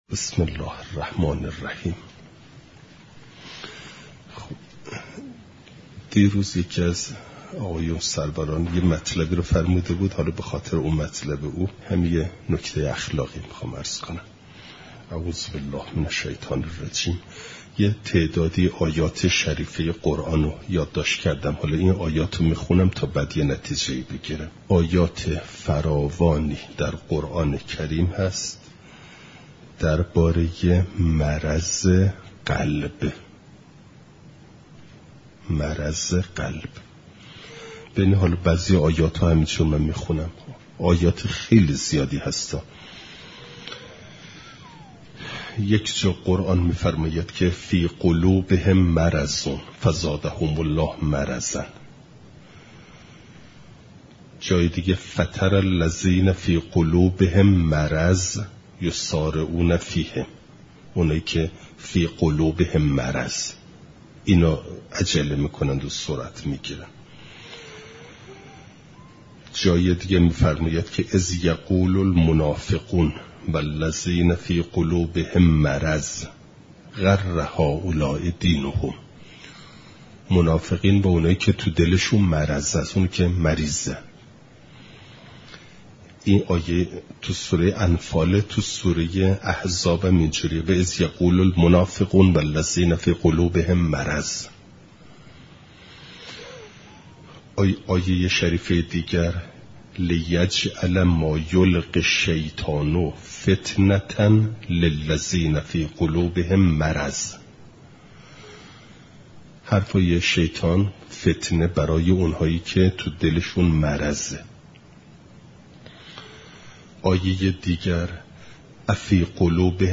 بعداز درس خارج فقه چهارشنبه ۲۶ آذرماه ۹۹